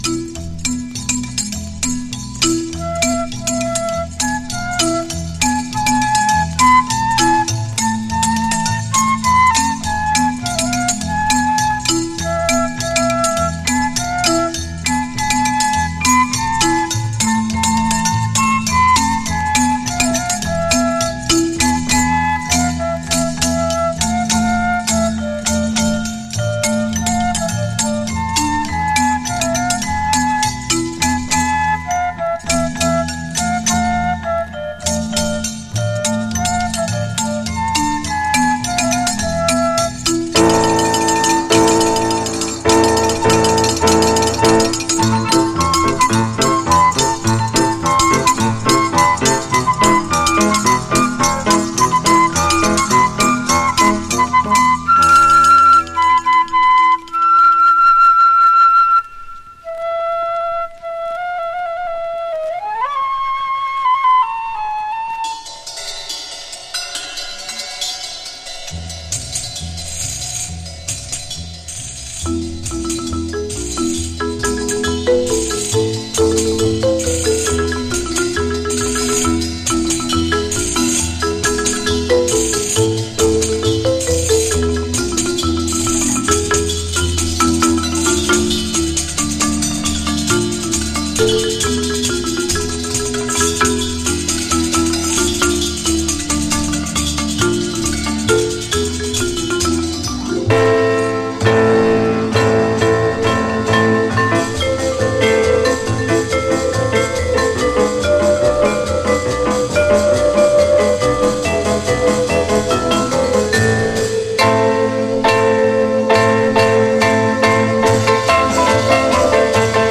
エキゾチカ・クラシック！